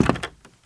High Quality Footsteps
Wood, Creaky
STEPS Wood, Creaky, Walk 20.wav